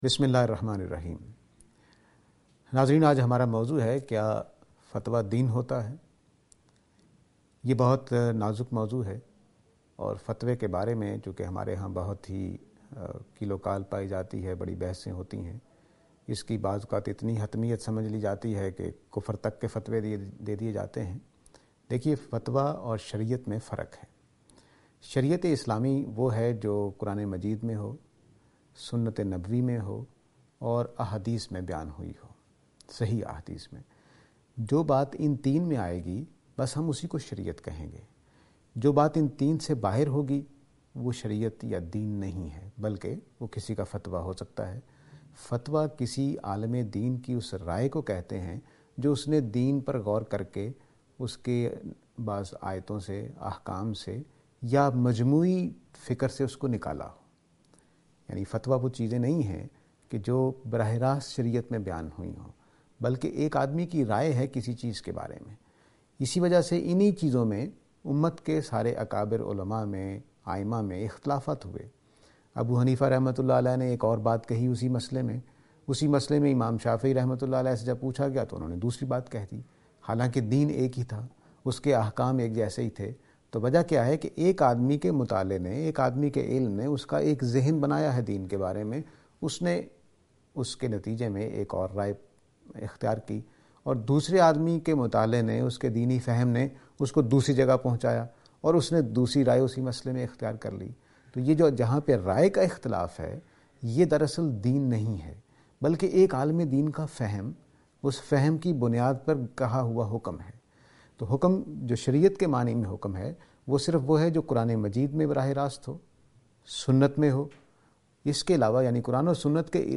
This lecture is and attempt to answer the question "Is fatwā religion (Deen)?".